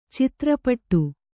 ♪ citra paṭṭu